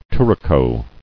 [tou·ra·co]